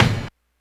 kick01.wav